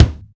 Index of /90_sSampleCDs/Roland L-CD701/KIK_Natural Kick/KIK_Natural K2
KIK SHARP 0E.wav